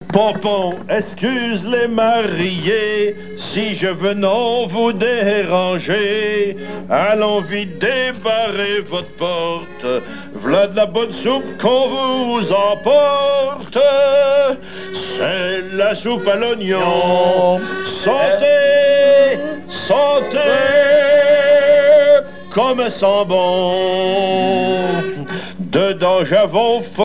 émission La fin de la Rabinaïe sur Alouette
Pièce musicale inédite